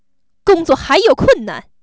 angry